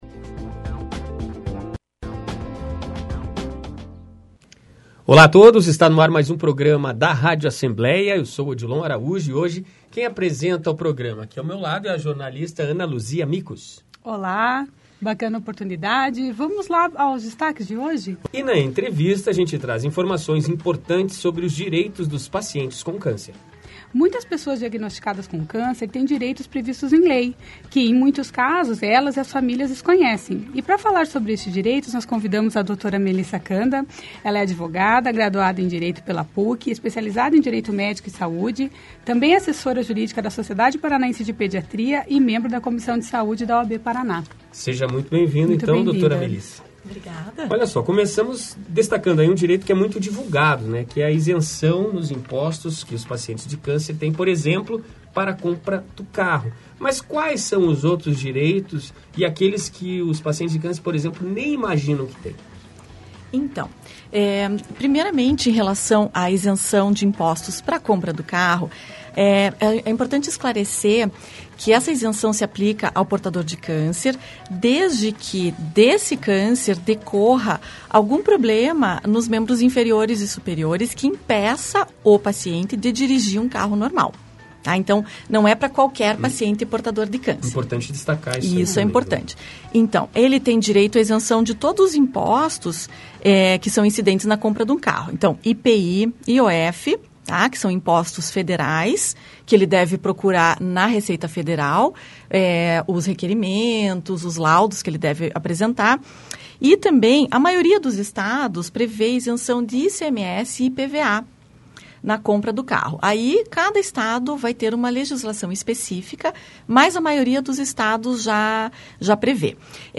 Advogada explica principais direitos legais de pessoas com câncer